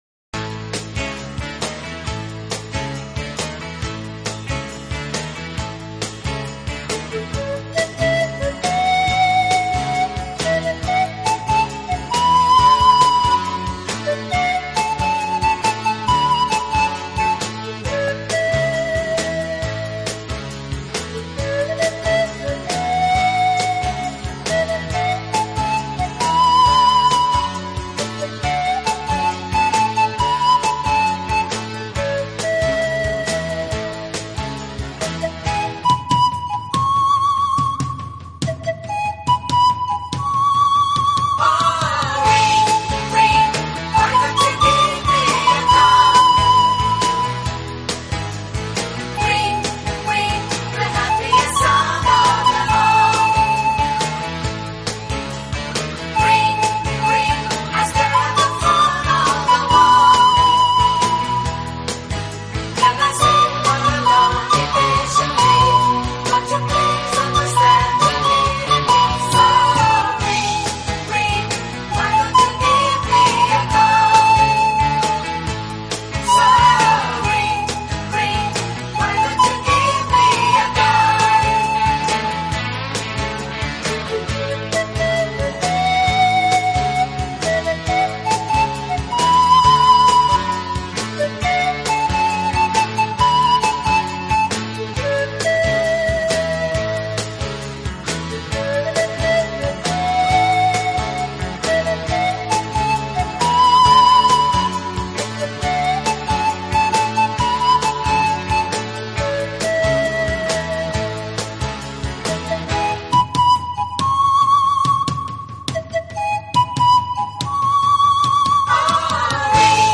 音乐类型：Instrumental
自然的乐器，充满了大自然奔放、和谐的意境，又具有一种太空般虚幻、飘渺的音色。